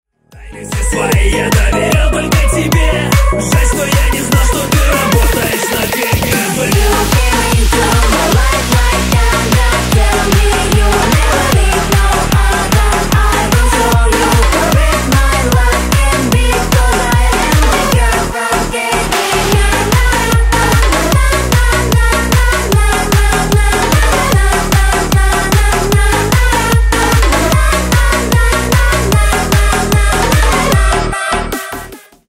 Танцевальные
весёлые